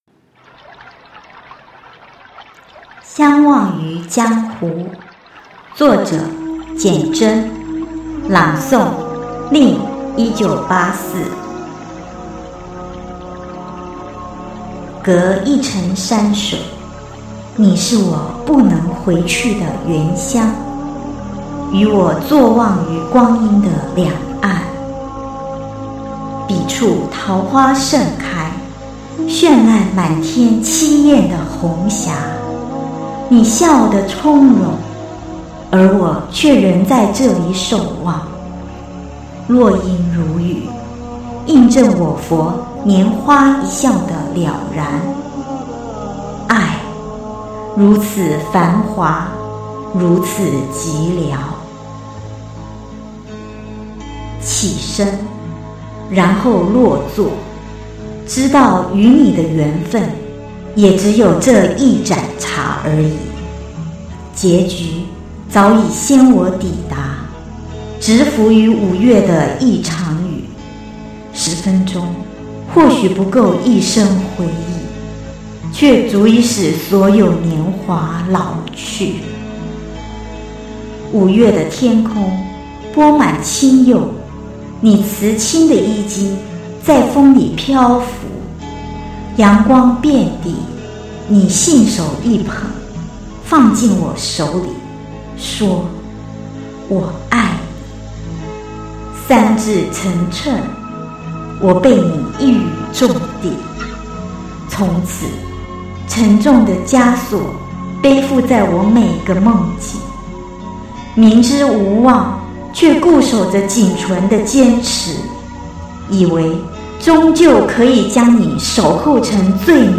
朗诵 相忘于江湖 文：简嫃